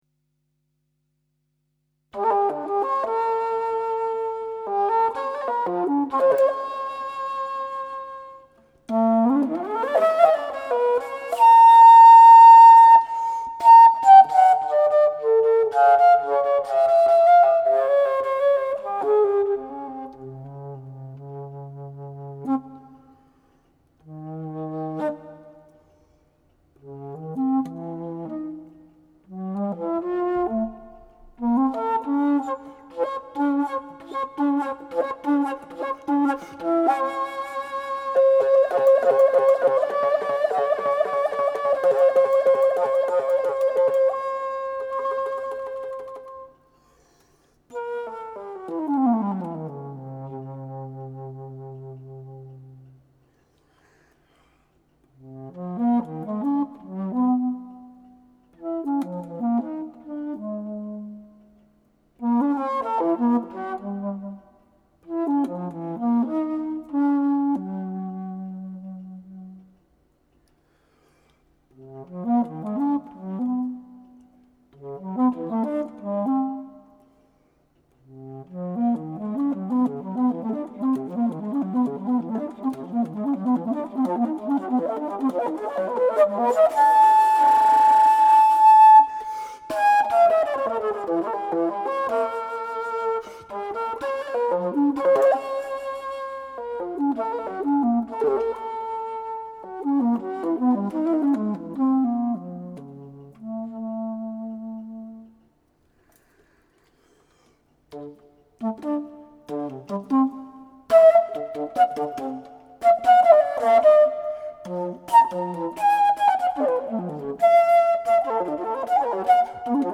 Bass Flute